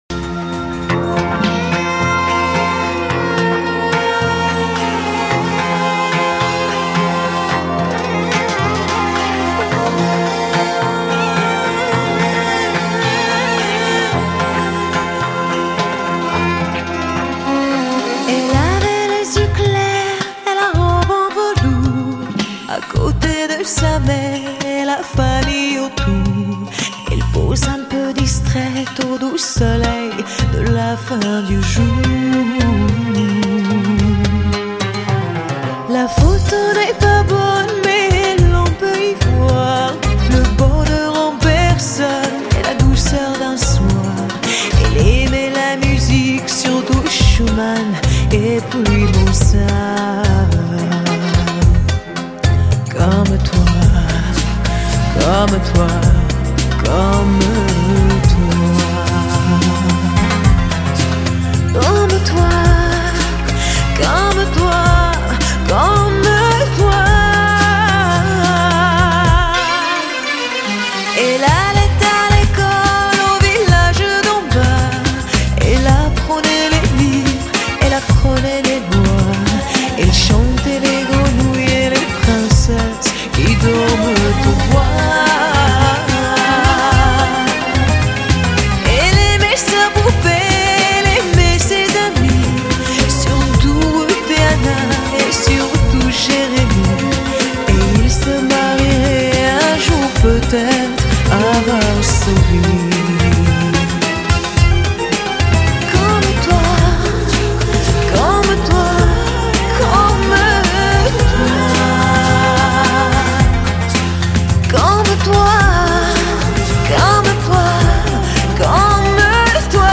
从1996年营业至今，吸引许多时尚、艺术与艺文人士的青睐，店内播放的音乐也以弛放心灵、充满东方漫游情境音乐为主。